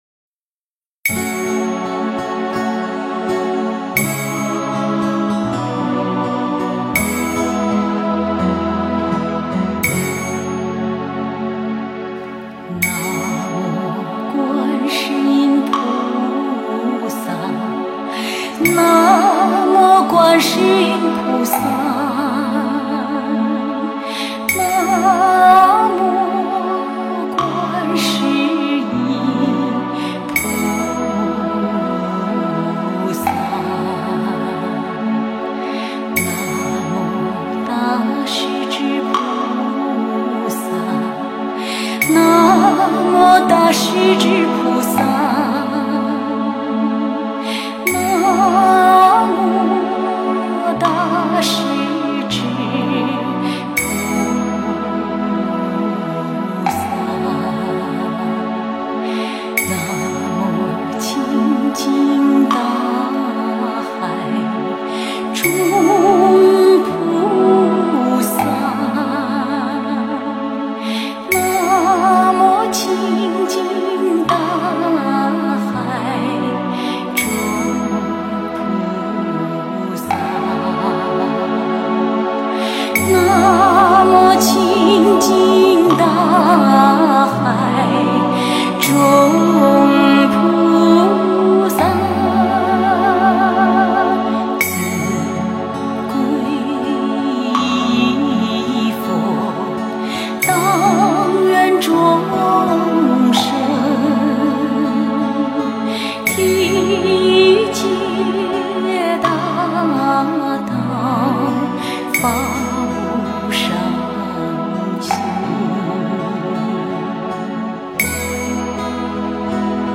佛音 诵经 佛教音乐 返回列表 上一篇： 飞天吉祥(赞佛偈